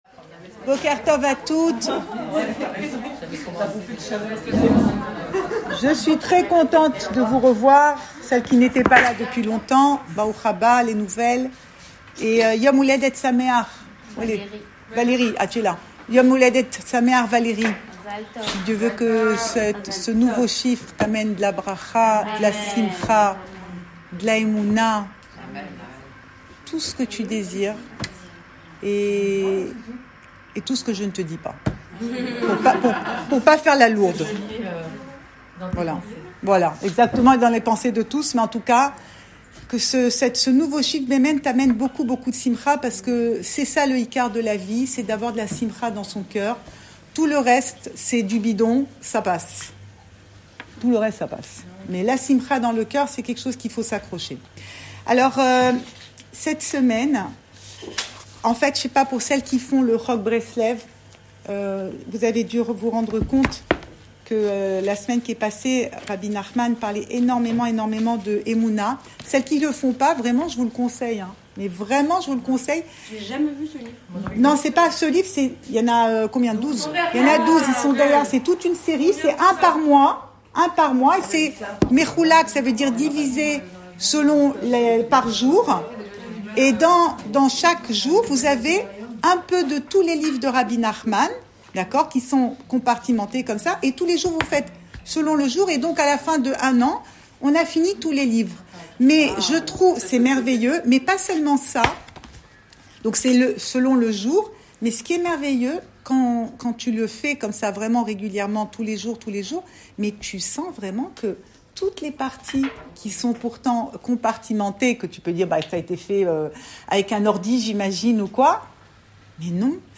Cours audio Le coin des femmes Pensée Breslev - 18 janvier 2023 18 janvier 2023 Des idées noires (mais particulièrement éclairantes). Enregistré à Tel Aviv